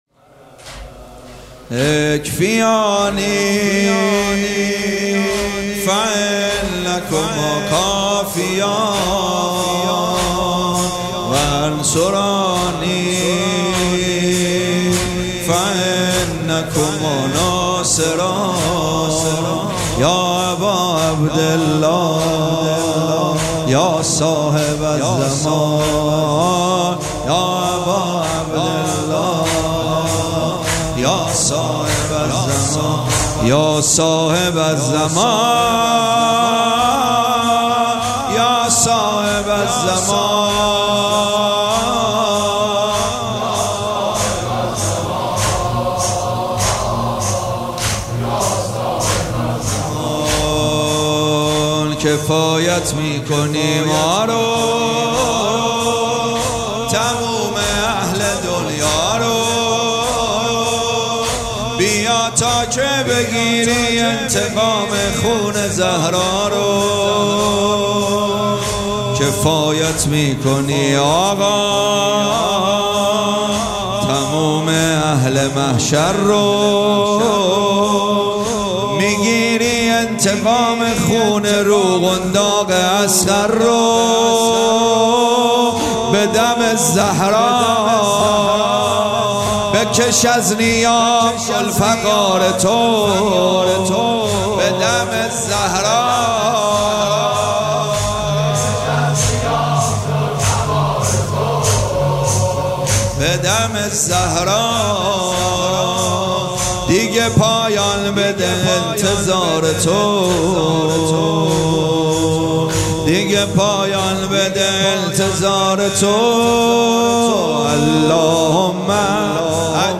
مداحی به سبک زمینه اجرا شده است.